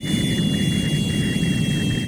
deceleration1.wav